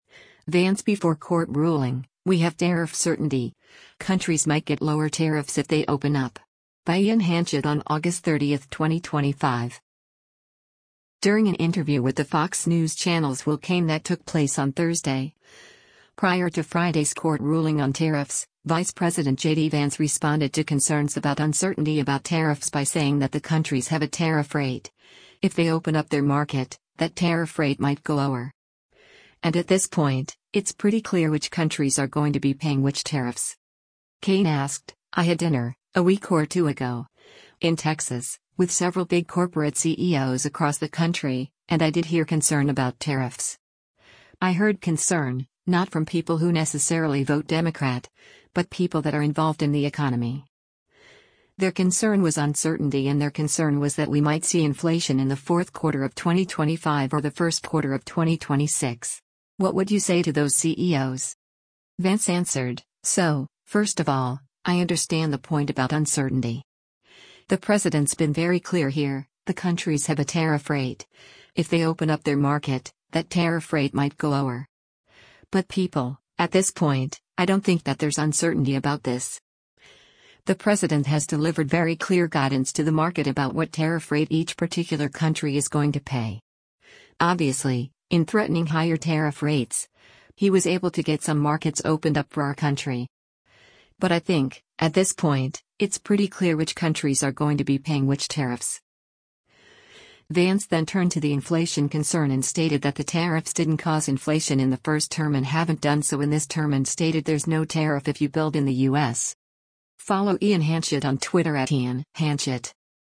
During an interview with the Fox News Channel’s Will Cain that took place on Thursday, prior to Friday’s court ruling on tariffs, Vice President JD Vance responded to concerns about uncertainty about tariffs by saying that “the countries have a tariff rate, if they open up their market, that tariff rate might go lower.” And “at this point, it’s pretty clear which countries are going to be paying which tariffs.”